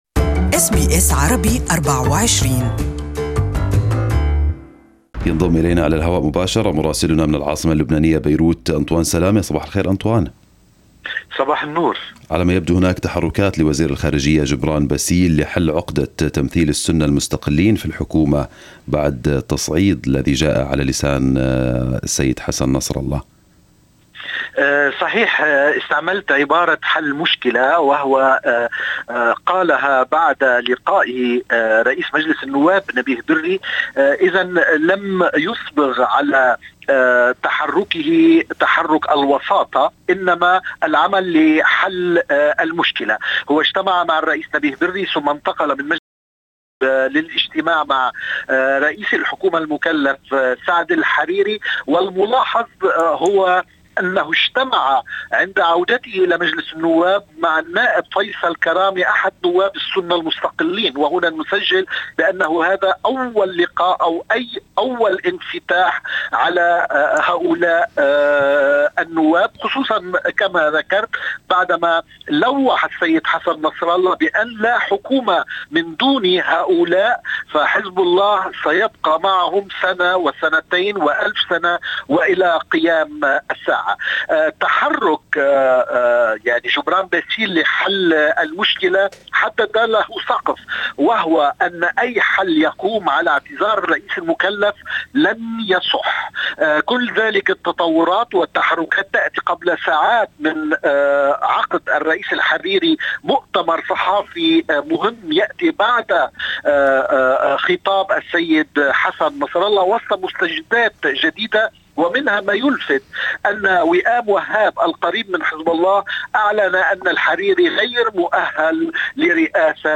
Beirut Correspondent has the details